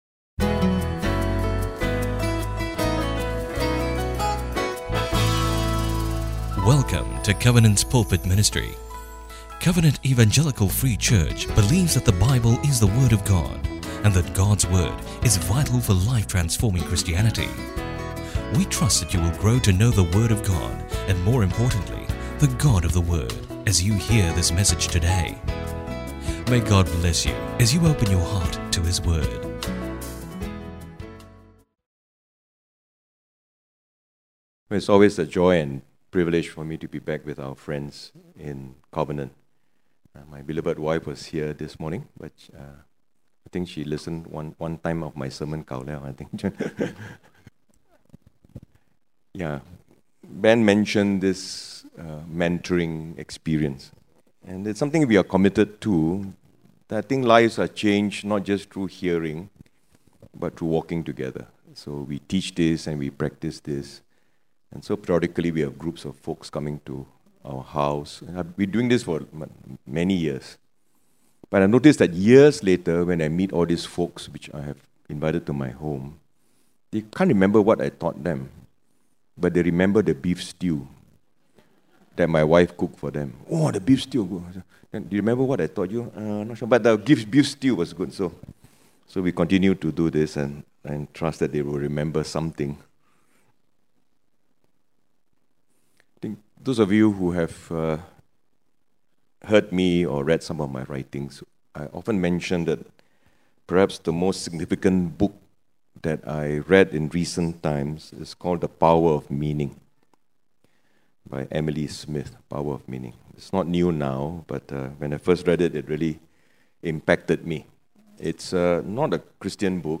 The Gift Of Love Covenant EFC Sermon Resources podcast